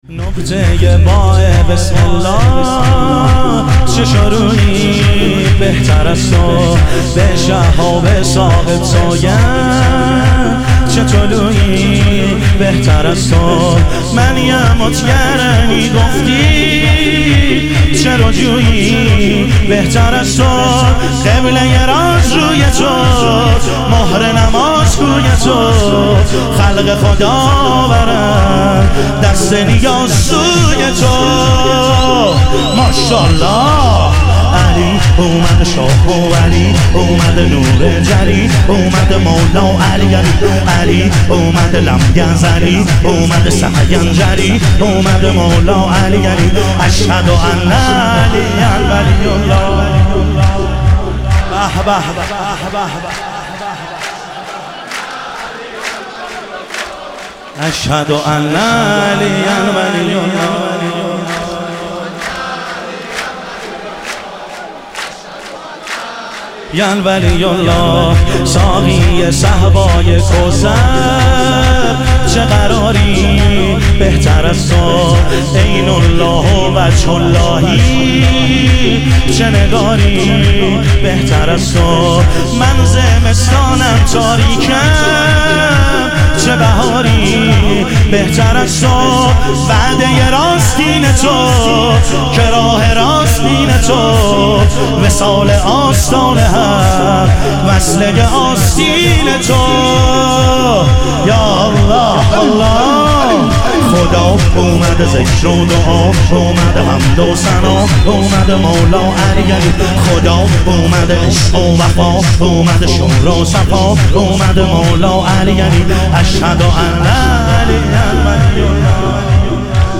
ظهور وجود مقدس حضرت امیرالمومنین علیه السلام - شور